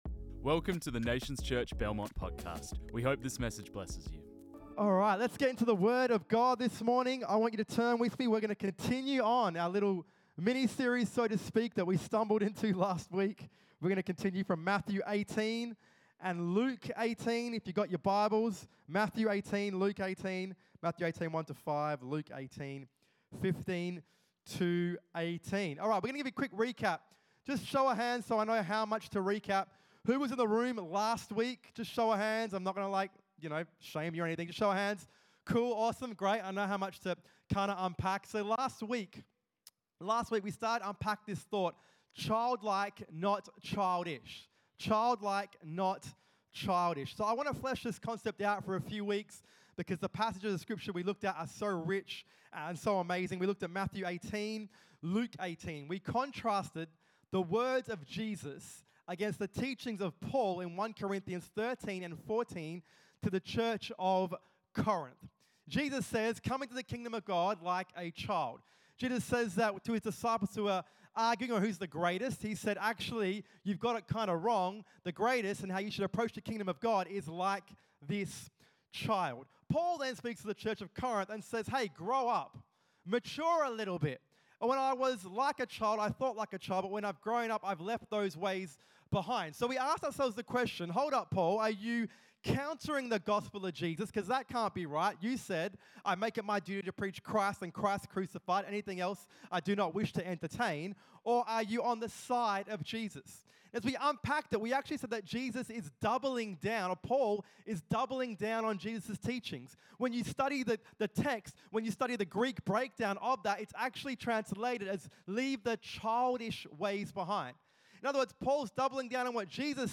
This message was preached on 20 October 2024.